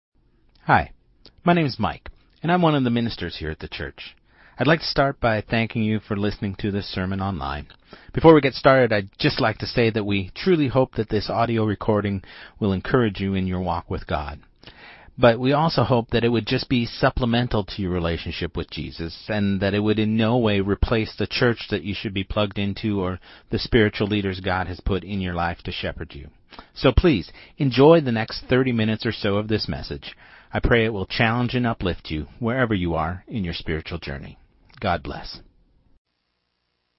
Sermon2025-08-17